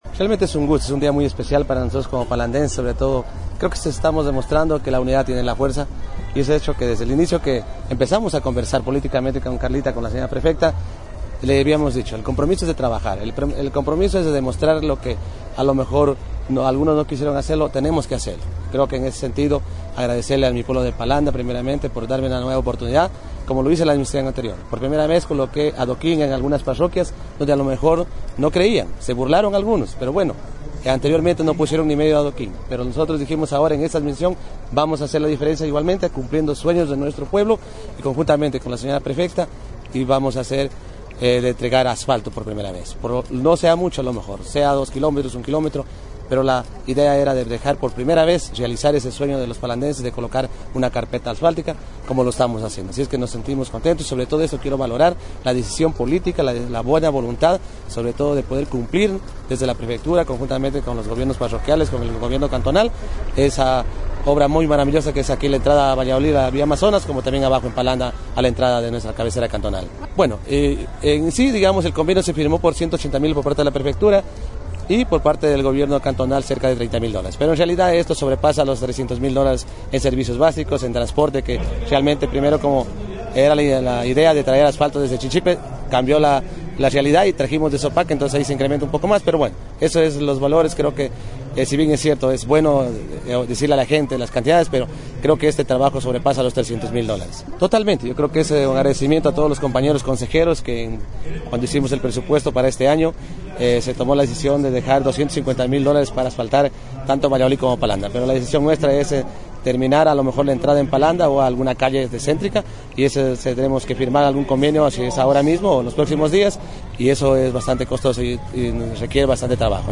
SEGUNDO JARAMILLO, ALCALDE PALANDA
SEGUNDO-JARAMILLO-ALCALDE-PALANDA.mp3